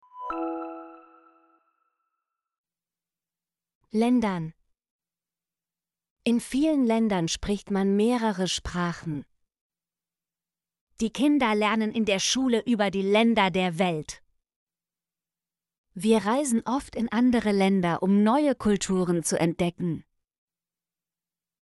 ländern - Example Sentences & Pronunciation, German Frequency List